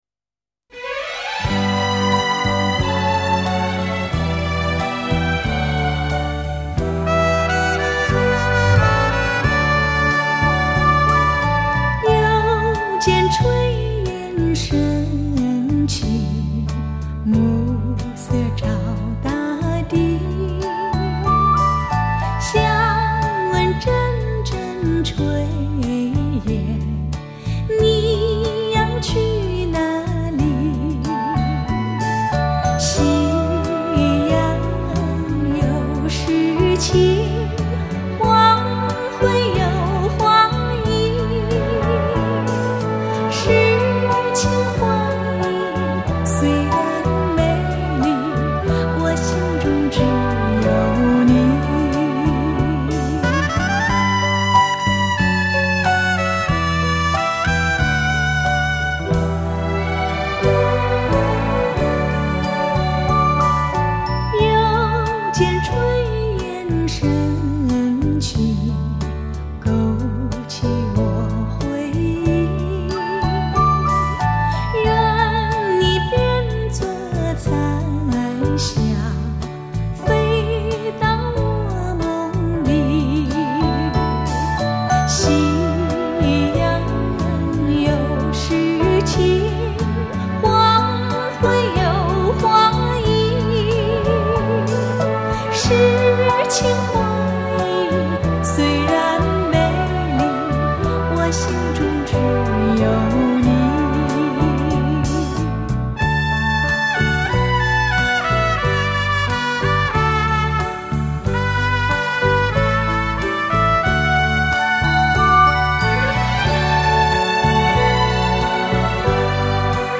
SACD的透彻而富底蕴的音色魅力